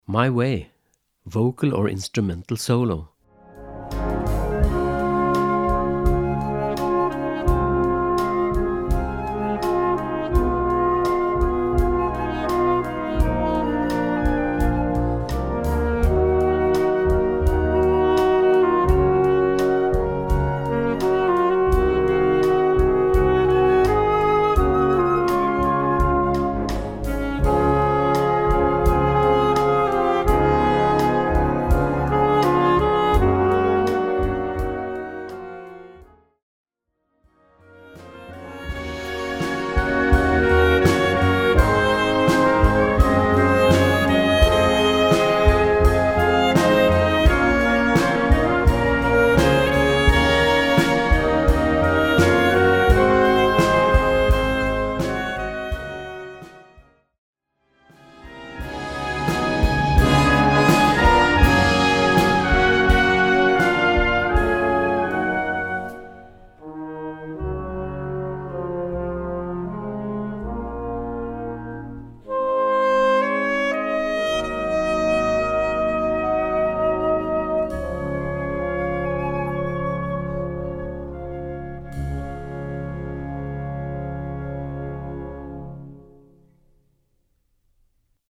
Gattung: Modernes Jugendwerk
Besetzung: Blasorchester